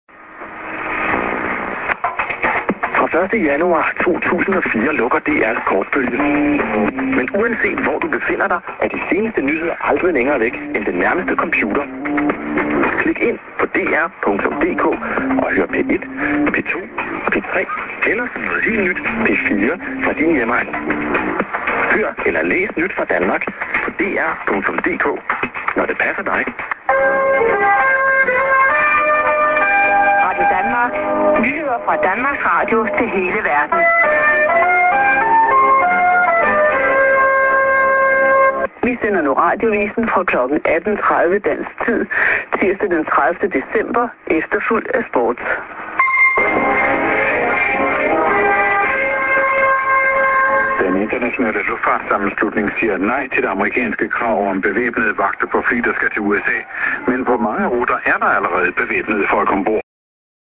s/on prog:man->30'25":ANN(women:ID)->prog